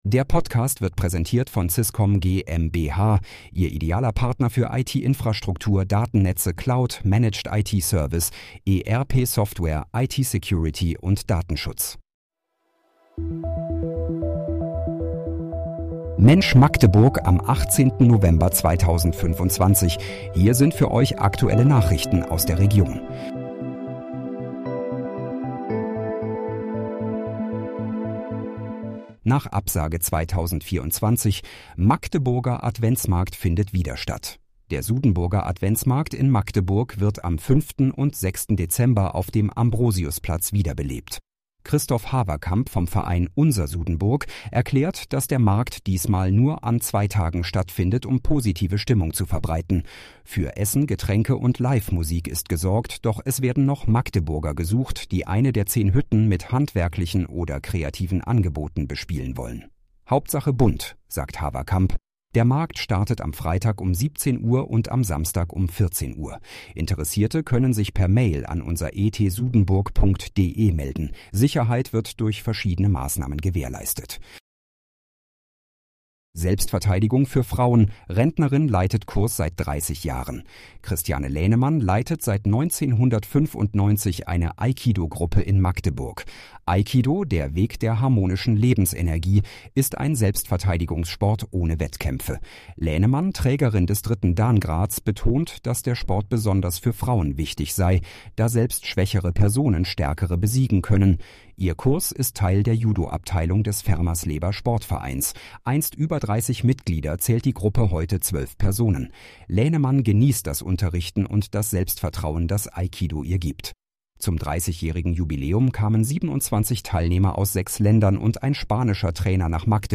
Mensch, Magdeburg: Aktuelle Nachrichten vom 18.11.2025, erstellt mit KI-Unterstützung ~ Mensch, Magdeburg – aktuelle Nachrichten Podcast